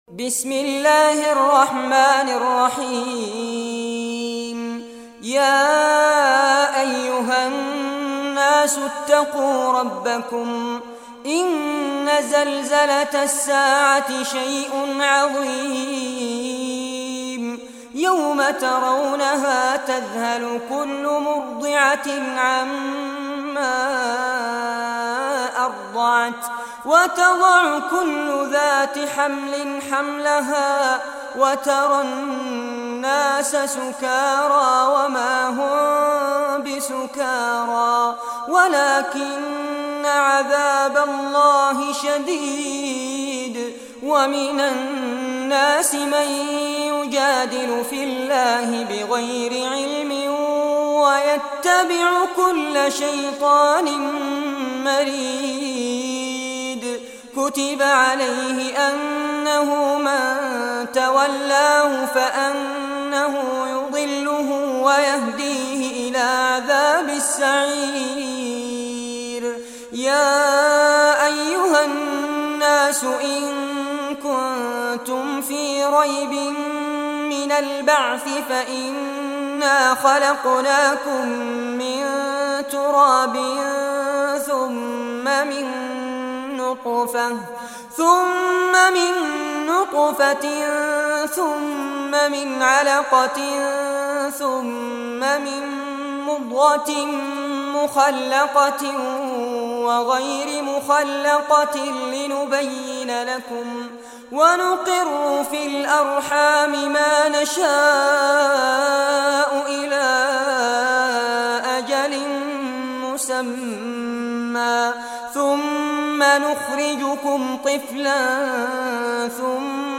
Surah Al-Hajj Recitation by Sheikh Fares Abbad
Surah Al-Hajj The Pilgrimage, listen or play online mp3 tilawat/ recitation in Arabic in the beautiful voice of Sheikh Fares Abbad.